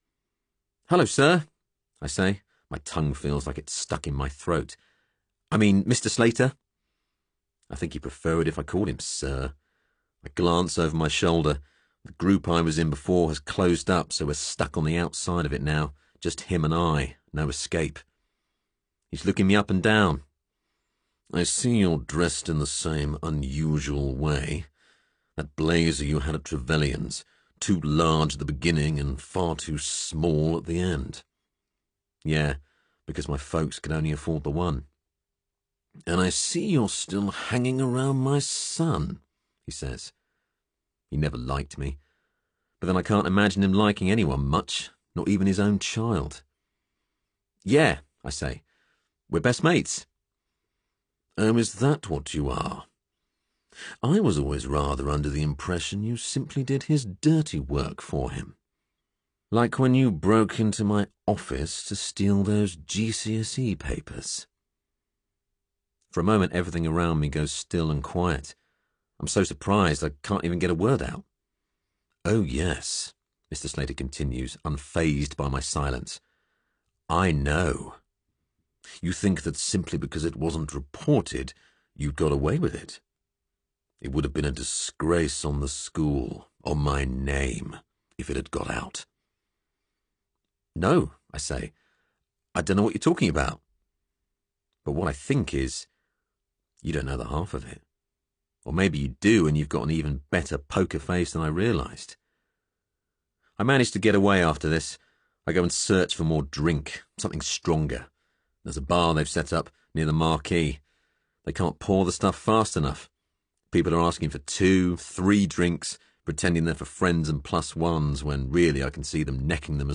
30/40's London/Neutral, Deep/Engaging/Comedy
• Audio Books